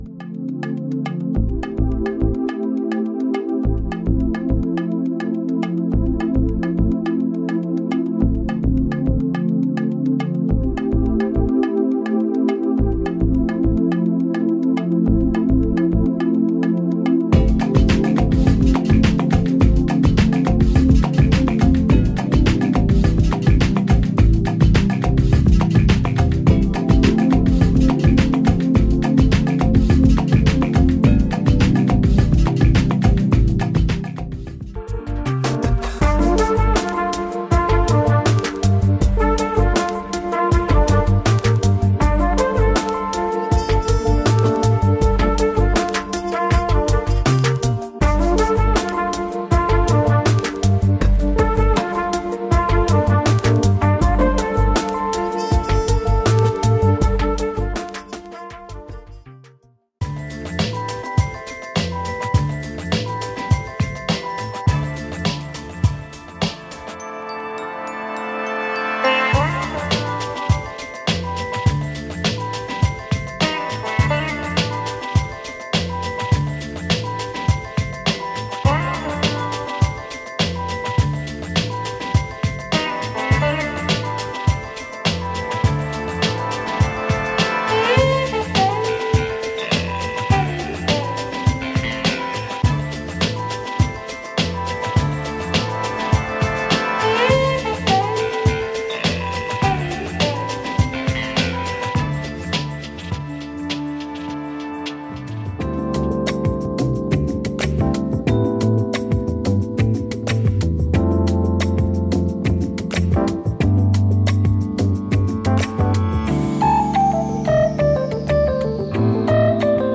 Wine Bar Lounge Music
Chill Lounge Music